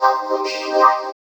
VVE1 Vocoder Phrases 03.wav